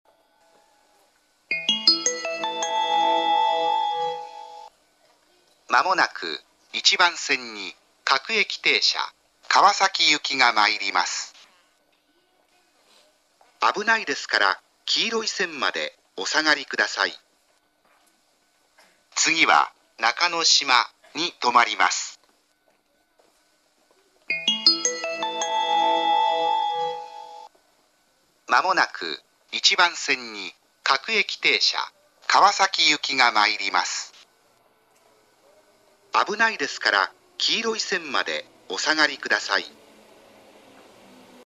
１番線接近放送
接近放送は「各駅停車　川崎行」です。